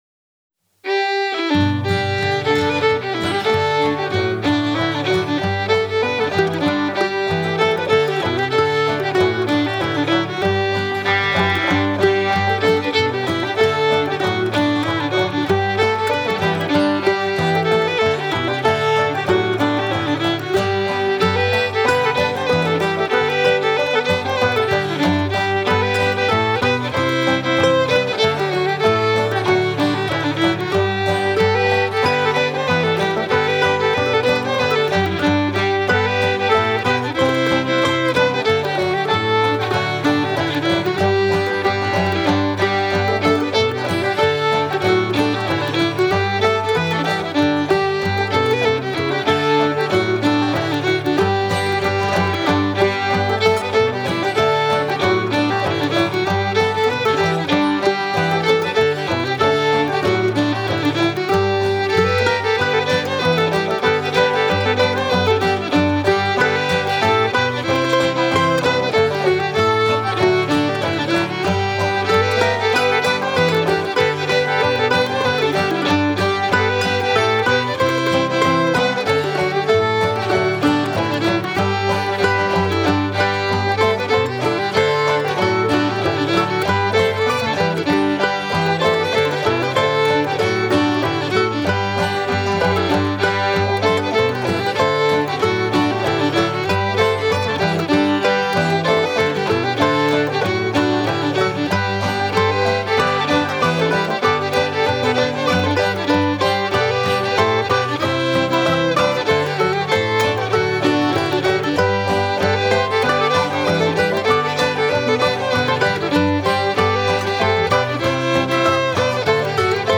Old Time for sure but we like to think of ourselves as a transitional string band.
We recorded these with our guitar player